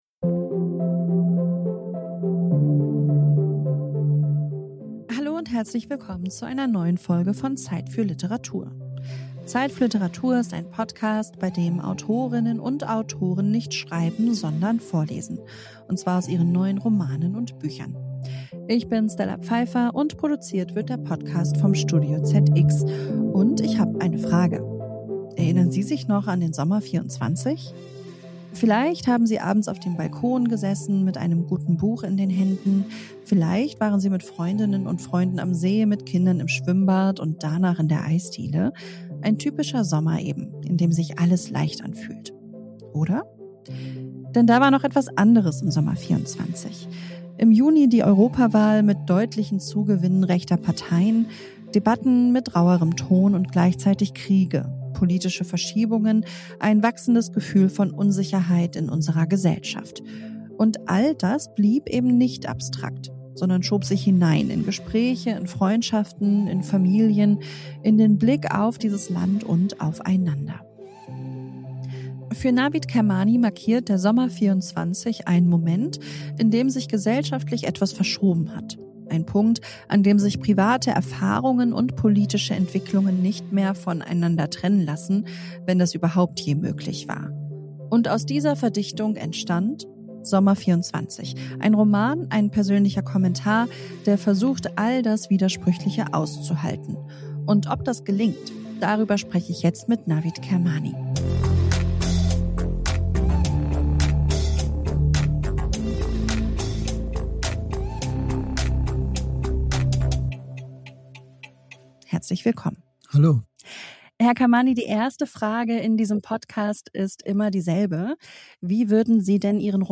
Nach dem Gespräch können Hörerinnen und Hörer direkt in den Text einsteigen: mit einer eine Hörprobe aus dem gleichnamigen Hörbuch, erschienen im Argon Verlag und gelesen von Jens Harzer.